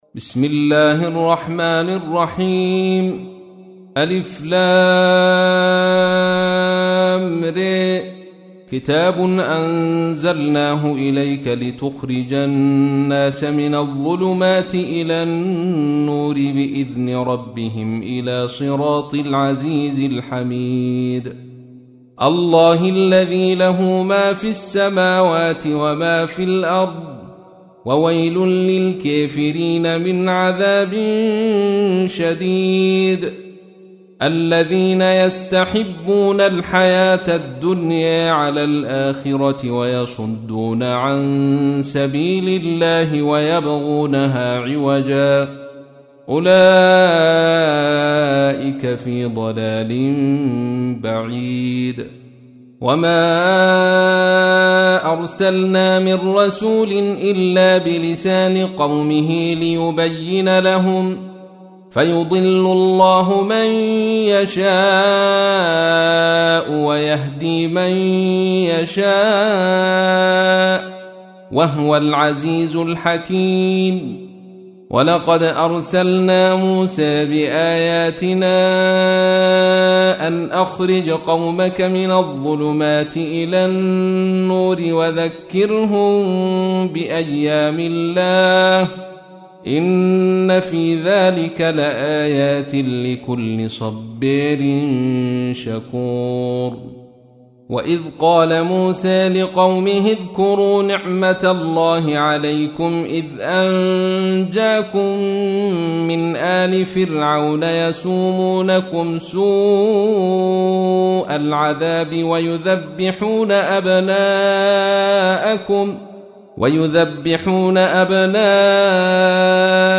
تحميل : 14. سورة إبراهيم / القارئ عبد الرشيد صوفي / القرآن الكريم / موقع يا حسين